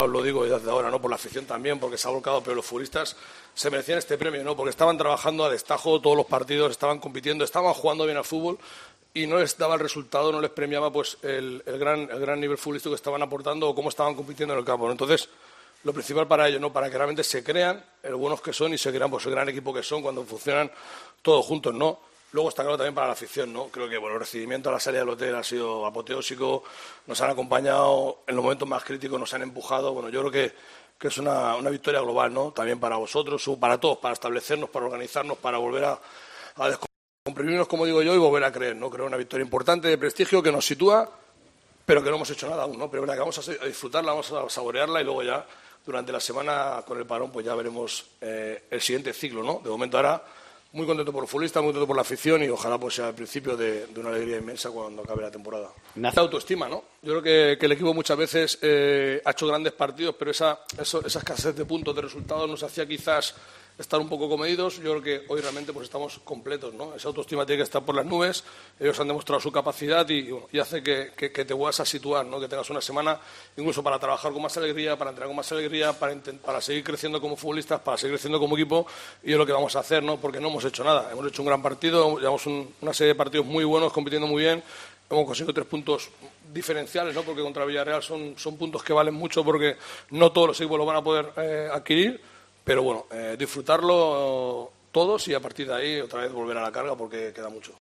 El técnico del Cádiz, Sergio González, explicaba tras el partido lo que suponía ganar así.